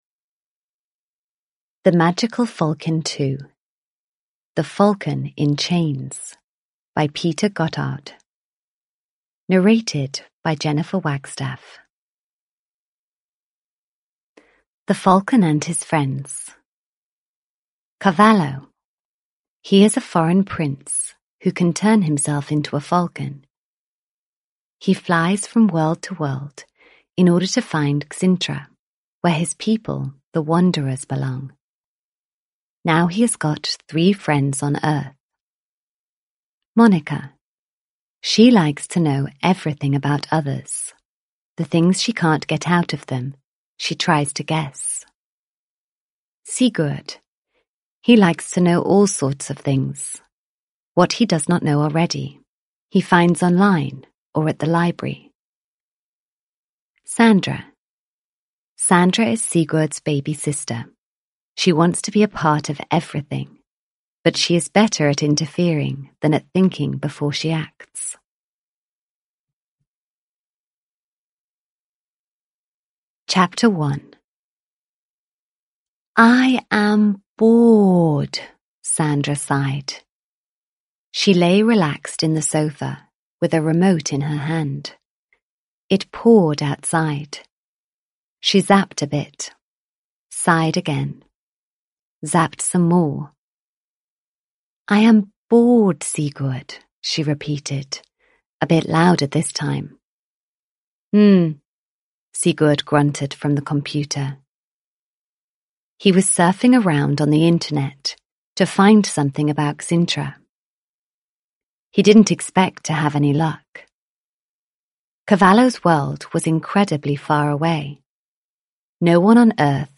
The Magical Falcon 2 - The Falcon in Chains (ljudbok) av Peter Gotthardt